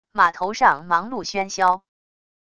码头上忙碌喧嚣wav音频